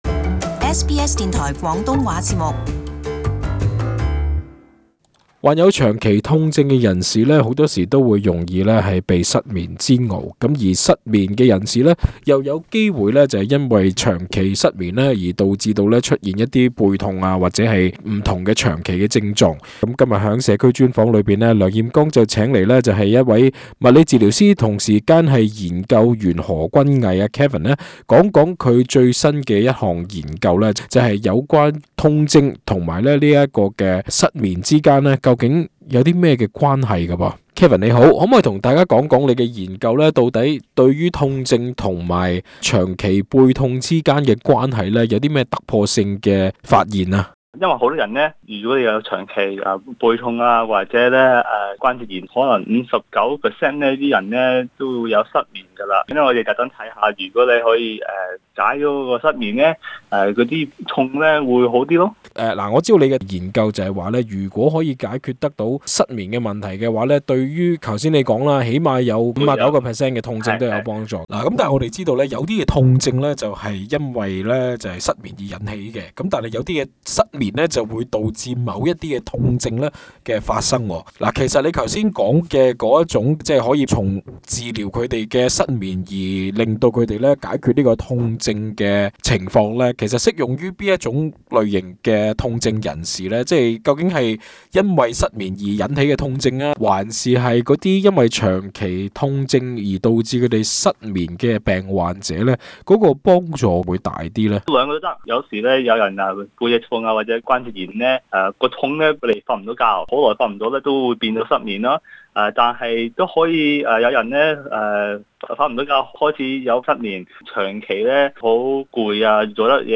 【社區專訪】研究：治療失眠或有助解決長期背痛